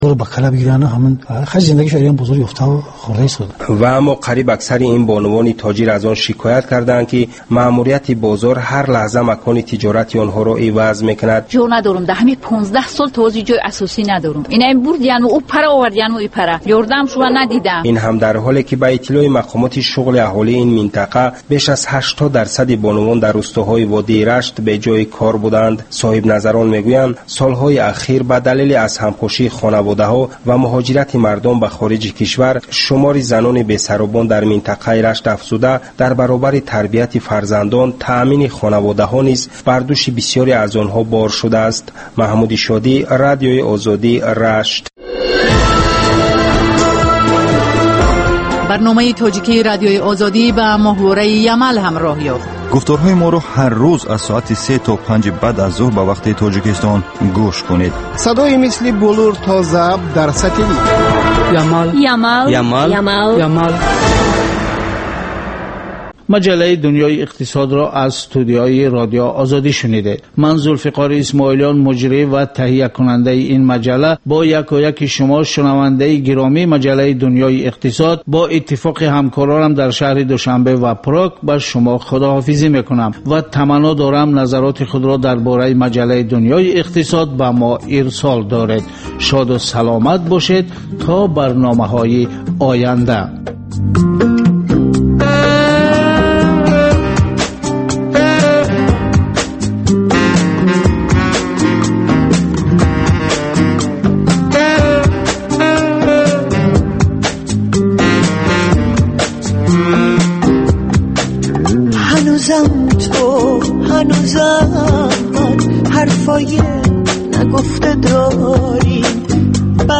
Маҷаллаи ғайриодӣ, ки ҳамзамон дар шакли видео ва гуфтори радиоӣ омода мешавад.